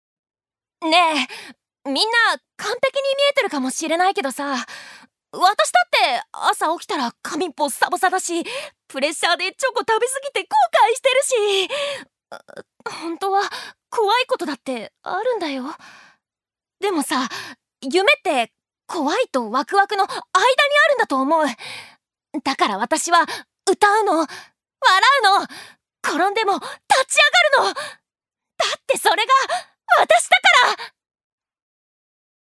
ジュニア：女性
セリフ２